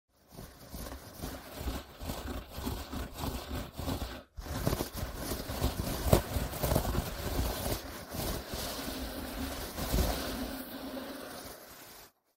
Звук царапанья ногтями по ковру